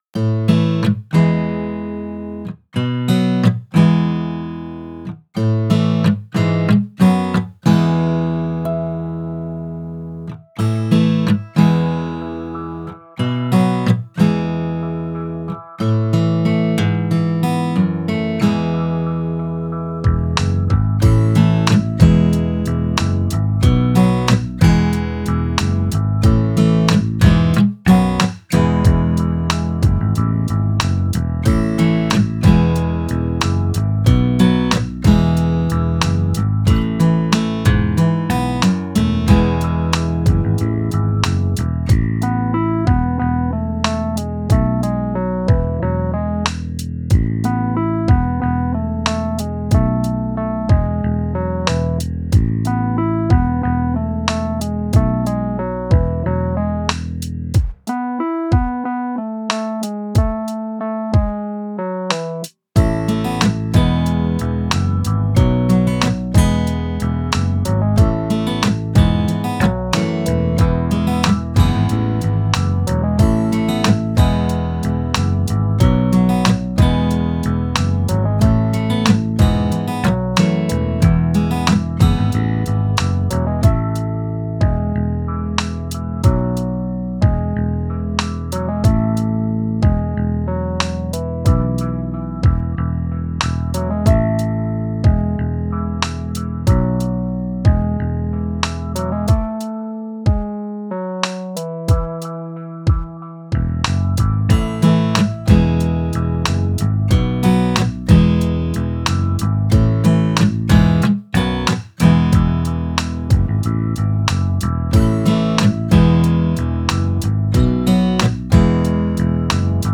【ほのぼの、ギター、かわいい、明るい、アコギ、ループ】イメージのオリジナルフリーBGMです、